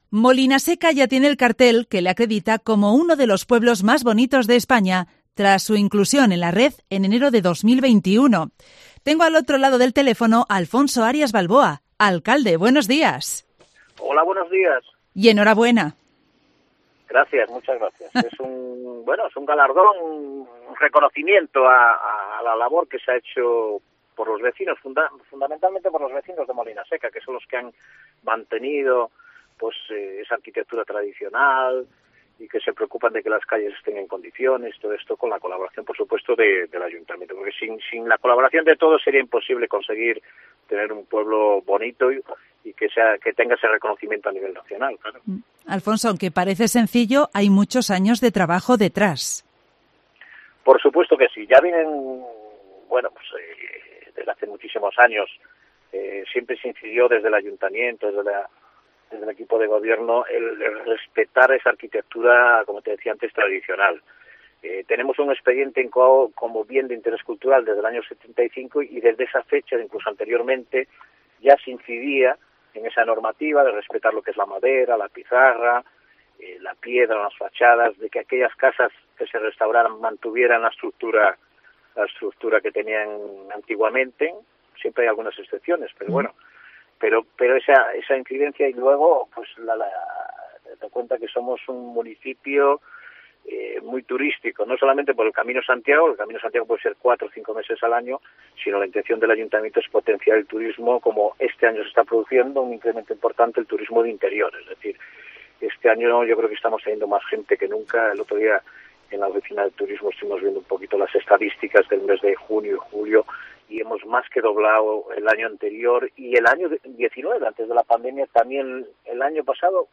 Alfonso Arias Balboa, Alcalde del Ayuntamiento de Molinaseca, explica en Cope que han sido muchos los años de trabajo para lograr este reconocimiento que les motiva para seguir trabajando cada día en la mejora de su pueblo, un galardón que ya está aumentando de forma muy importante la afluencia de turistas, un motor para garantizar el futuro de sus vecinos.